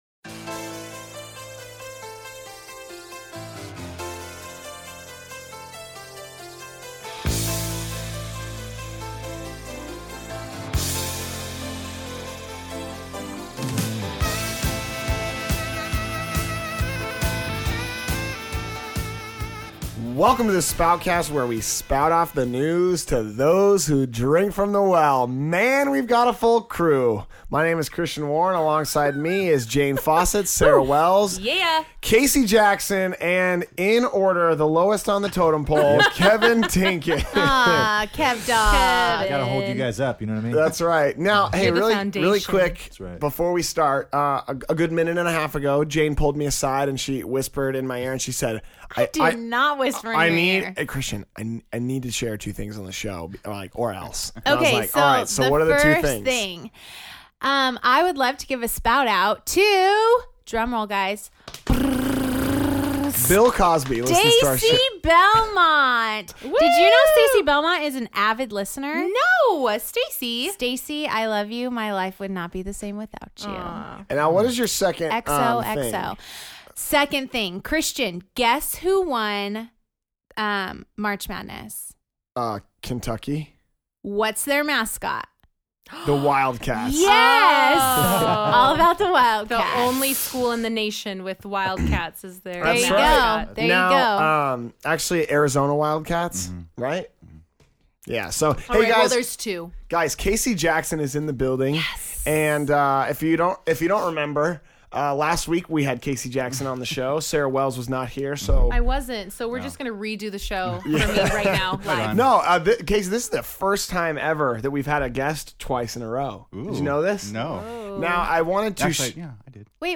The crew talks about the new art panels at each Campus, and discuss their Easter experiences. The Spoutcast Crew also discusses their thoughts on the sermon and how each of them responded to the message.